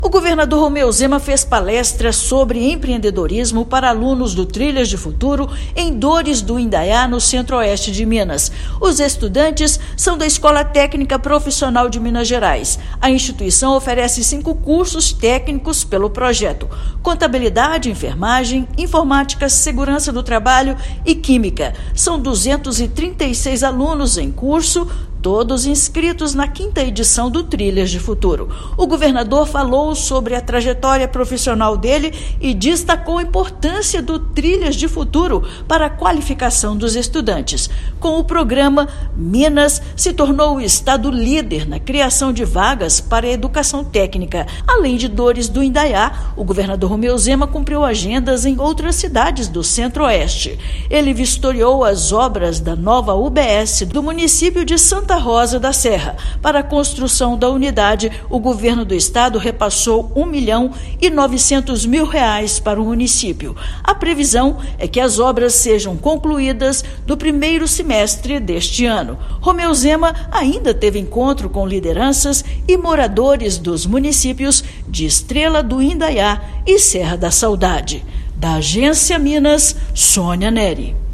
Além de palestrar para cerca de 200 estudantes em Dores do Indaiá, ele vistoriou obras de UBS em agendas no Centro-Oeste do estado. Ouça matéria de rádio.